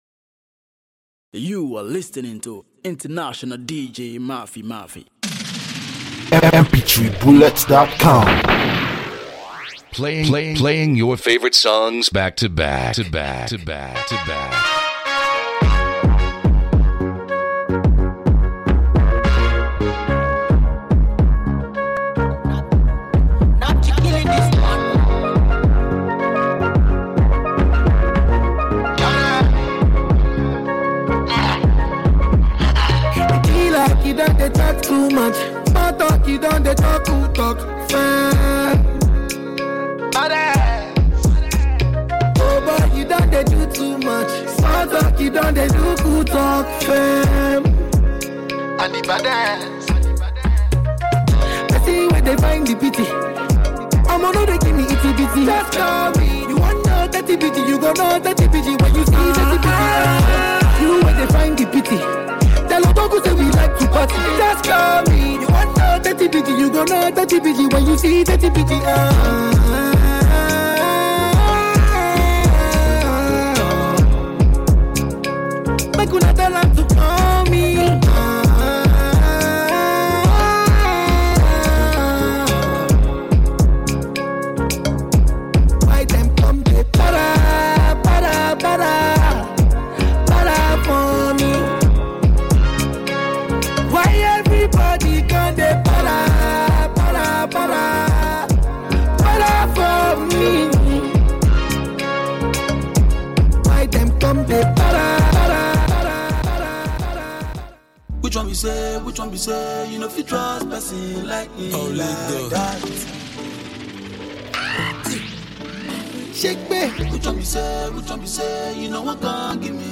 banger mix